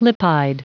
Prononciation du mot lipide en anglais (fichier audio)
Prononciation du mot : lipide